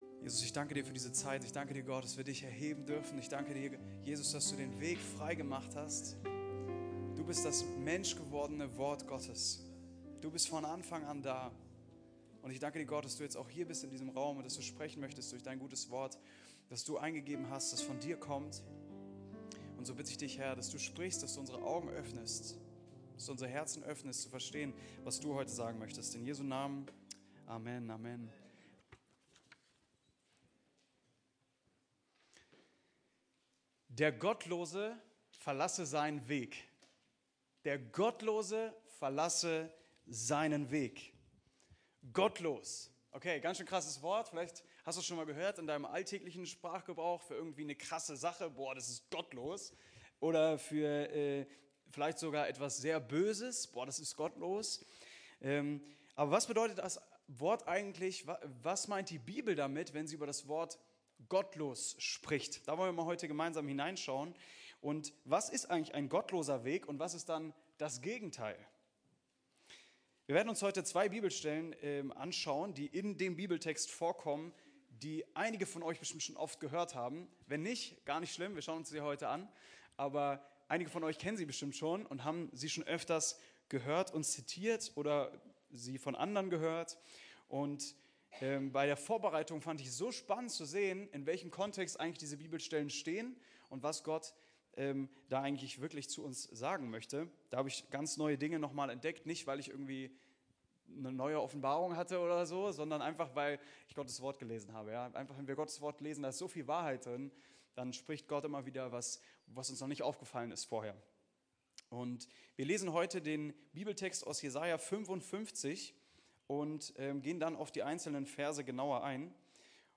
Kirche am Ostbahnhof, Am Ostbahnhof 1, 38678 Clausthal-Zellerfeld, Mitglied im Bund Freikirchlicher Pfingstgemeinden KdöR
Predigt